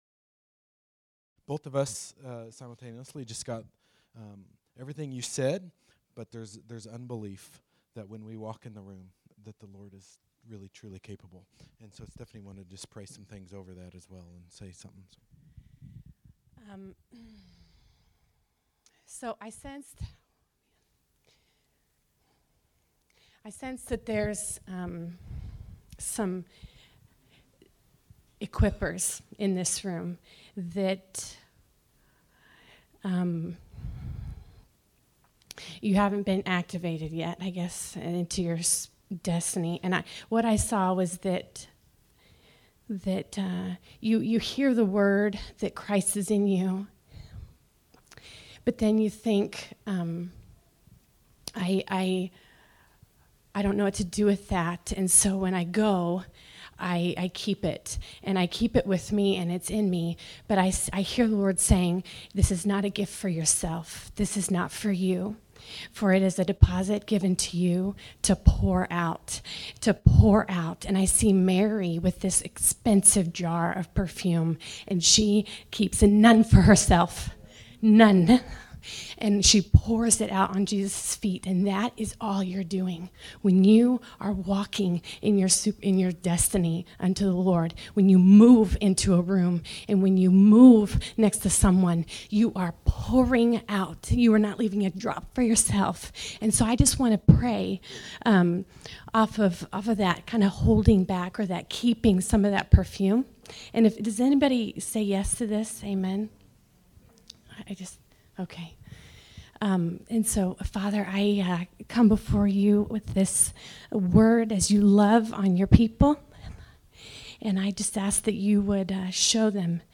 December 10, 2016      Category: Encouragements      |      Location: Wichita
Prophetic words of encouragement